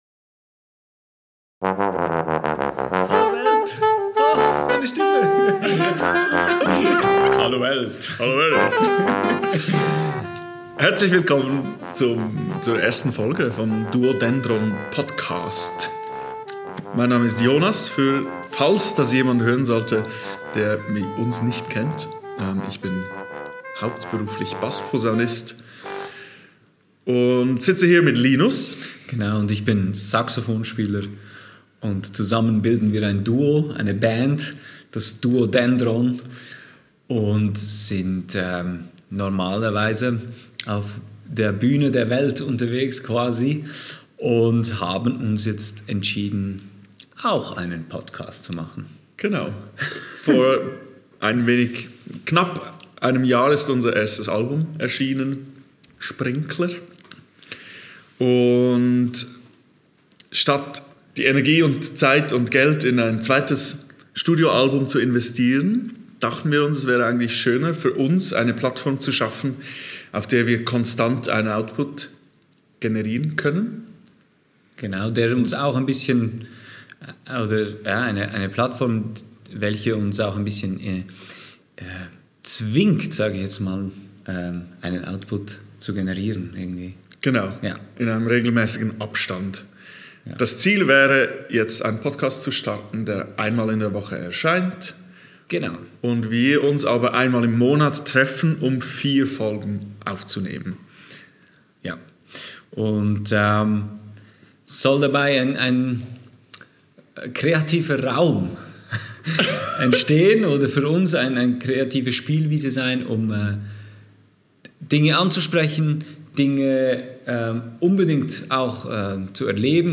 Aufgenommen am 19.03.2024 im Foyer des Theater Burgbachkeller Zug